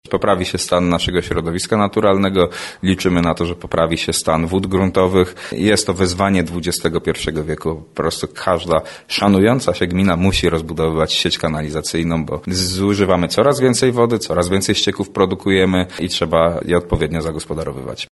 Oczyszczalnia ścieków w Izbiskach za ponad 13 mln złotych już na finiszu. Największa inwestycja w historii gminy Wadowice Górne- jak tłumaczy wójt Michał Deptuła – była konieczna, bowiem istniejąca dotychczasowa oczyszczalnia jest przestarzała i nie ma możliwości podłączenia większej ilości gospodarstw do niej.